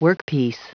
Prononciation du mot workpiece en anglais (fichier audio)
Prononciation du mot : workpiece